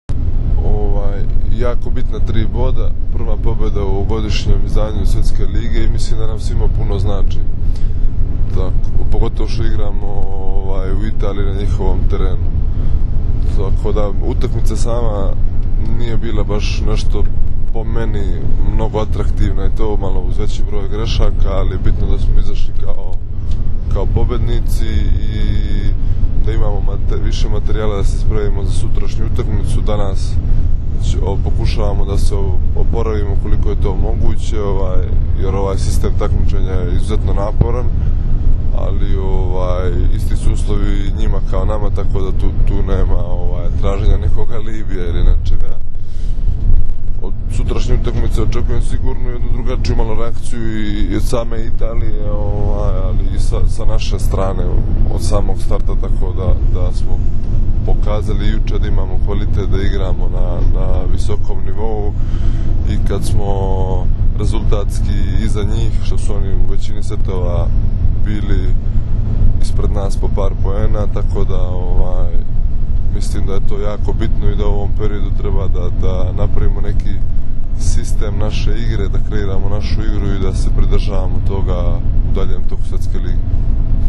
IZJAVA NEMANJE PETRIĆA